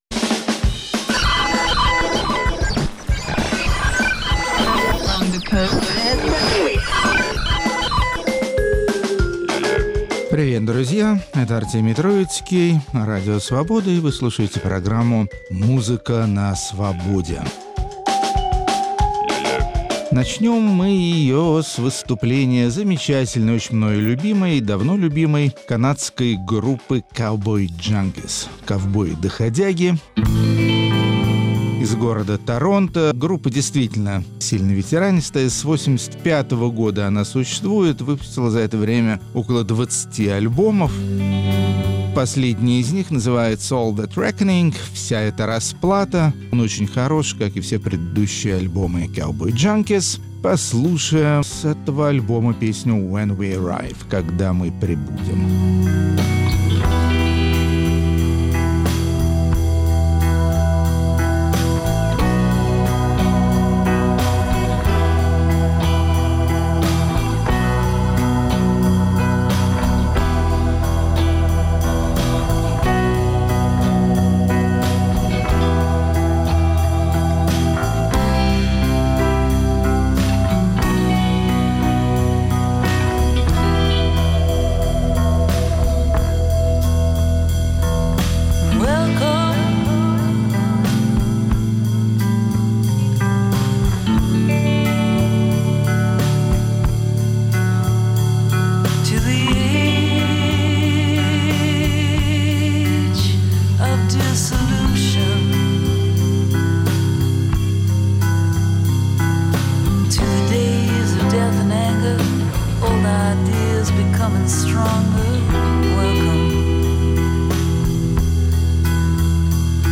Финский электронный композитор-экспериментатор